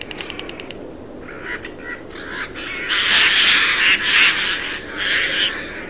Ghiandaia
Garrulus glandarius
Quando è indotta a volare, emette un rauco e sonoro ‘skreeeek’; anche vari suoni stridenti e gnaulanti.
Ghiandaia.mp3